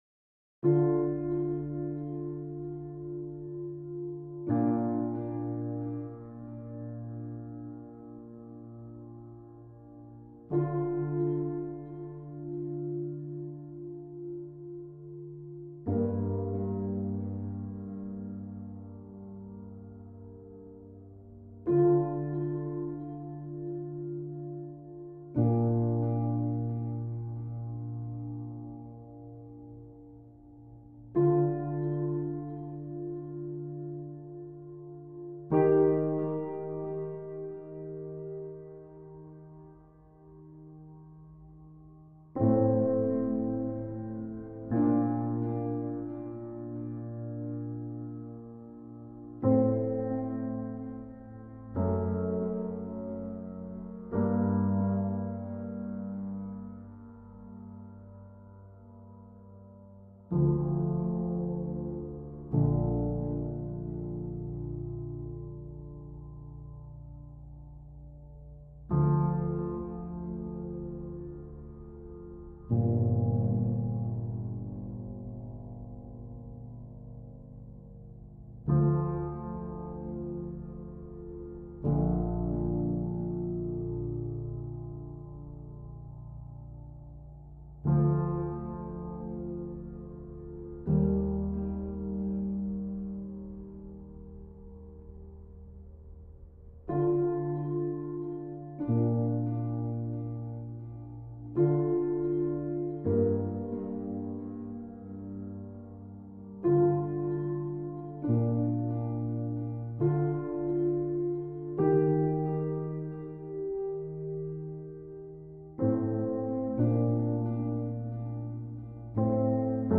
For piano and ambience.
An intimate and nostalgic music.
A tinge of Impressionism.